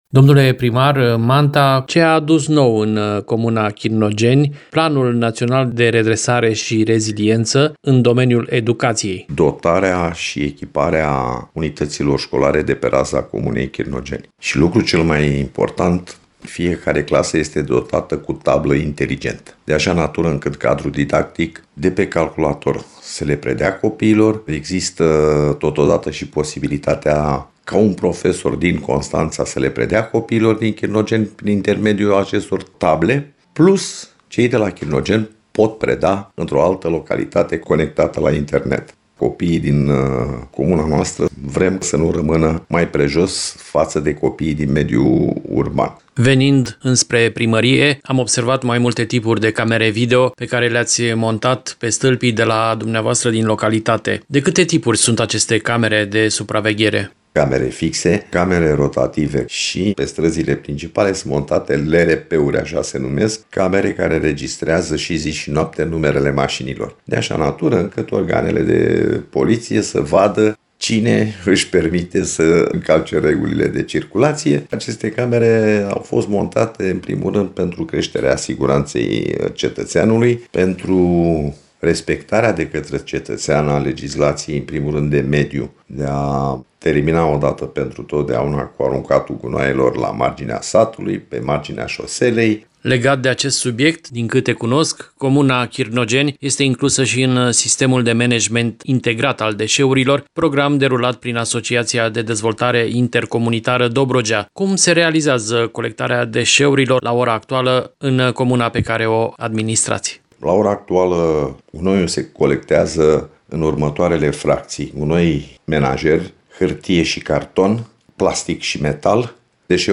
a discutat pe acest subiect cu primarul comunei Chirnogeni, Gheorghe Manta.